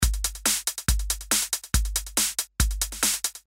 部落恍惚大鼓 Var 1
描述：部落Trance鼓Var 1 Trance Electro Electronic Beat
Tag: 140 bpm Trance Loops Drum Loops 295.48 KB wav Key : Unknown